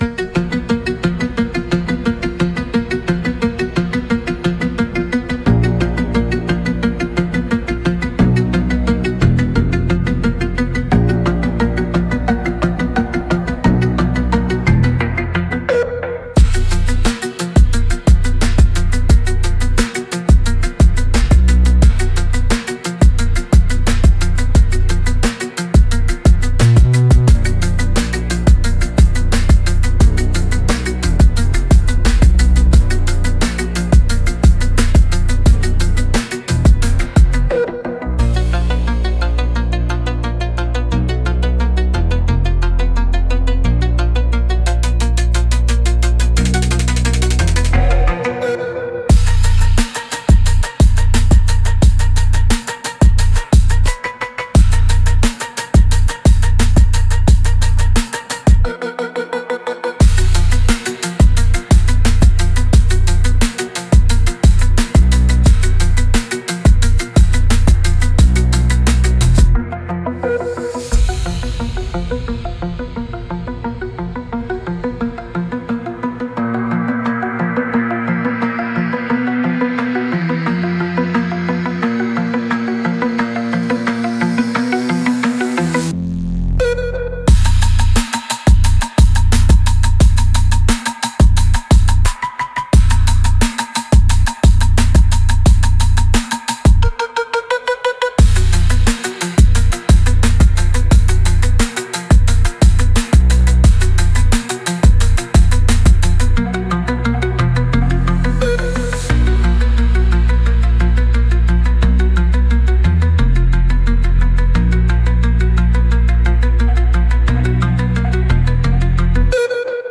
Instrumental - Drone of the Hollow - 2.18 Mins